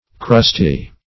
Crusty \Crust"y\ (-[y^]), a.